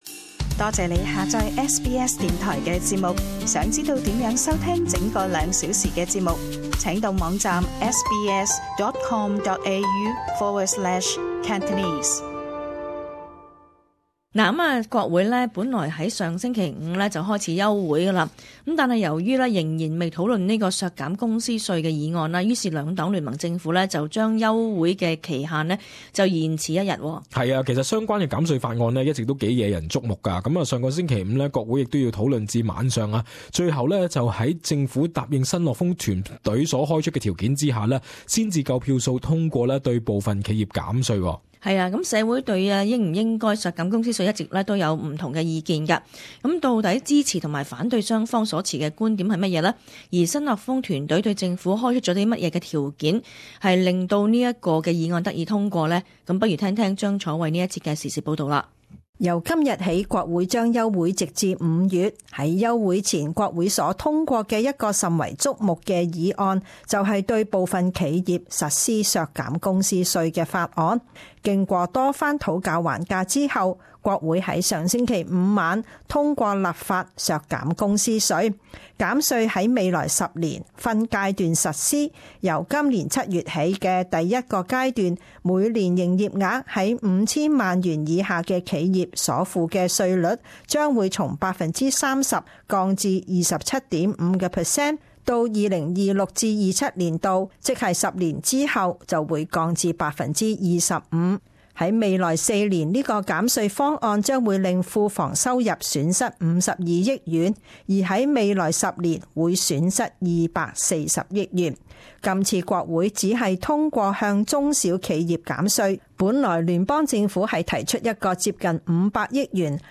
【時事報導】國會休會前通過企業減稅措施